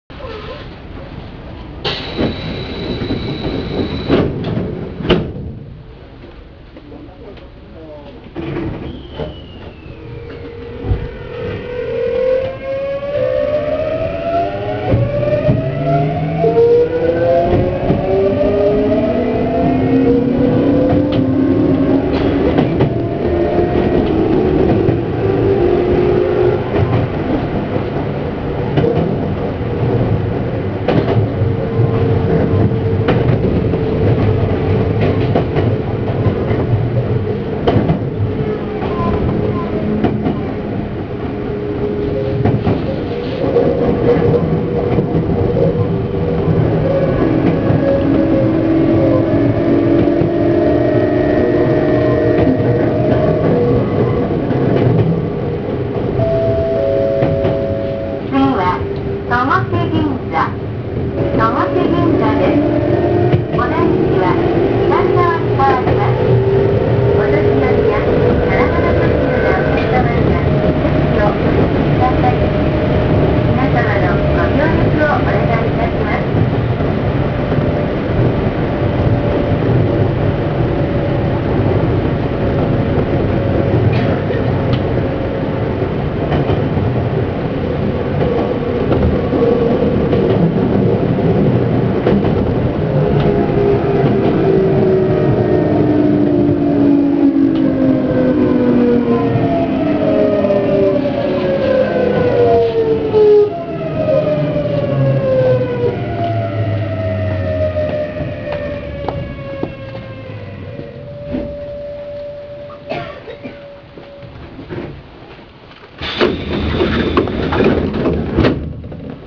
・7700系走行音
【多摩川線】大崎広小路→戸越銀座（1分59秒：648KB）
見た目とは裏腹なVVVFのモーター音。一応、東洋GTOを採用しているのですが、良く聞く音とは随分と音の聞こえ方が異なります。